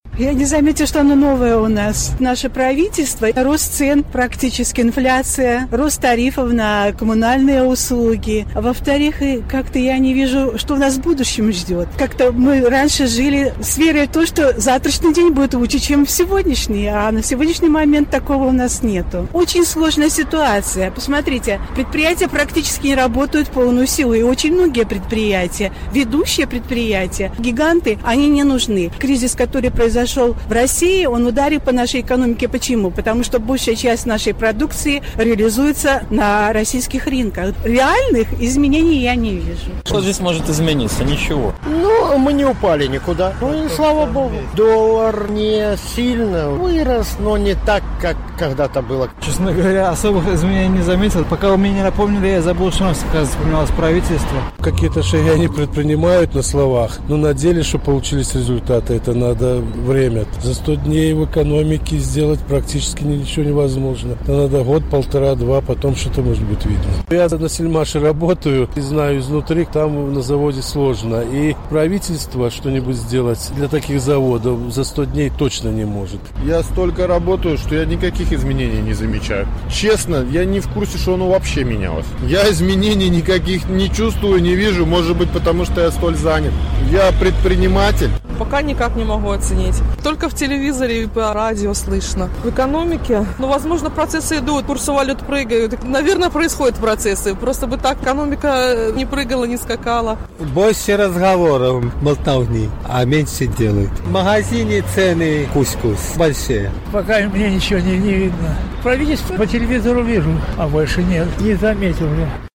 Адказваюць гамельчукі